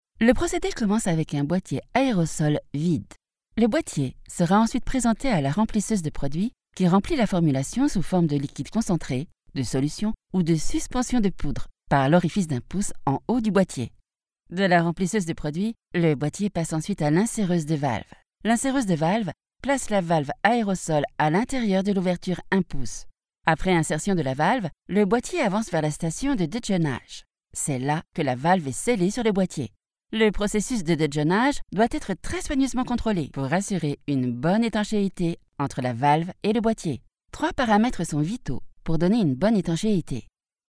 Vídeos Explicativos
Minha voz é calorosa, feminina e adequada para narração, elearning, audiolivros, audioguias, mas também alguns comerciais, jogos.
Micro Neumann 103
Uma cabine Isovox em uma cabine à prova de som